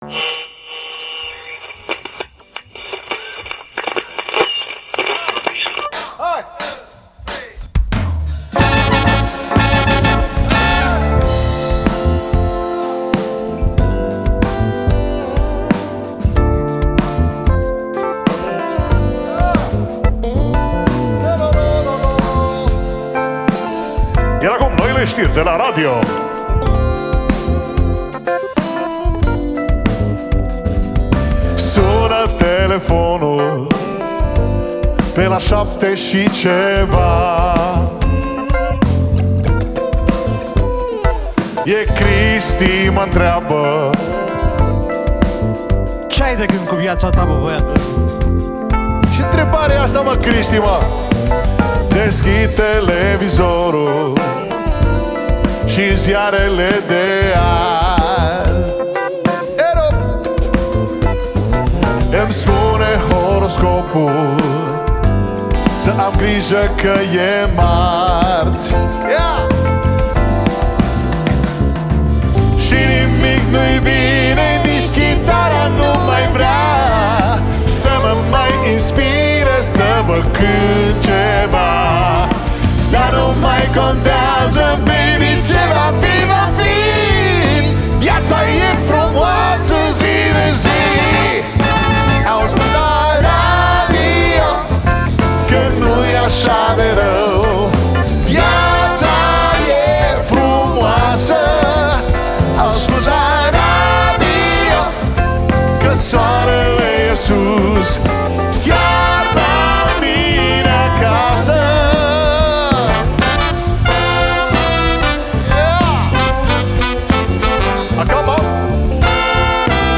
Pian
Chitara
Percutie